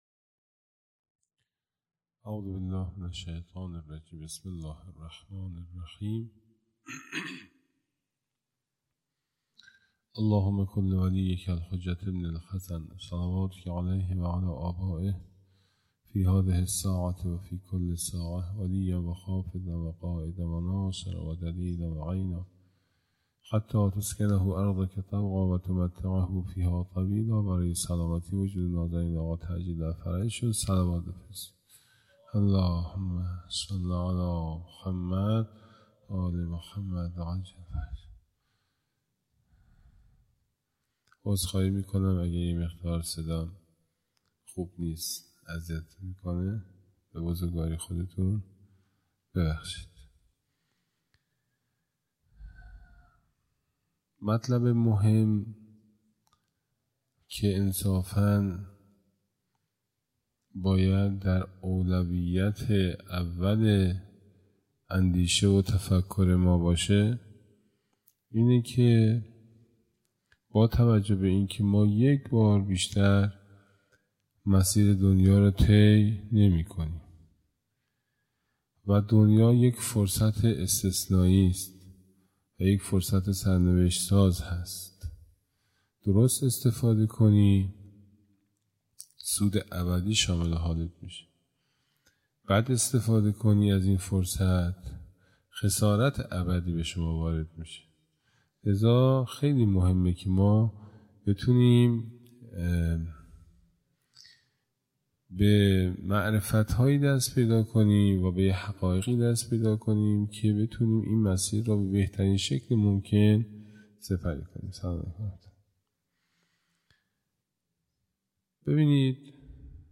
هیئت دانشجویی فاطمیون دانشگاه یزد
جلسه هفتگی